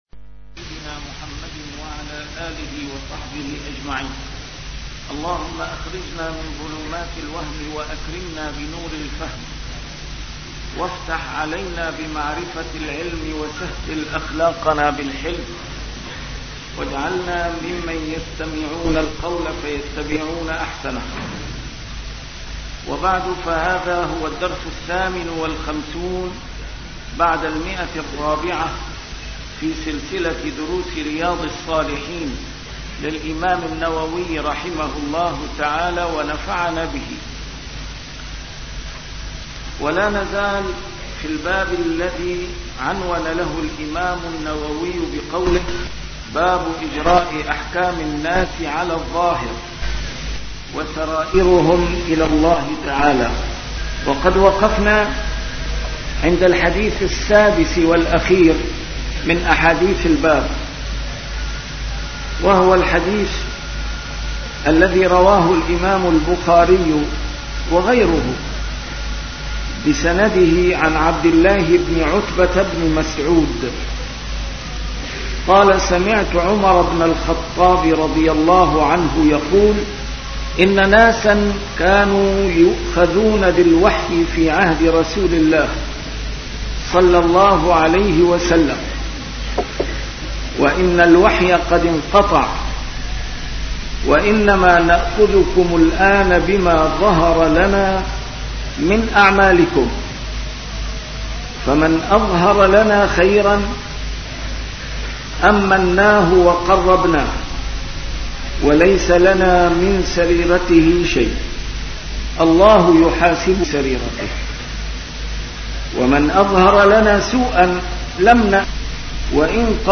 A MARTYR SCHOLAR: IMAM MUHAMMAD SAEED RAMADAN AL-BOUTI - الدروس العلمية - شرح كتاب رياض الصالحين - 458- شرح رياض الصالحين: الحكم بالظاهر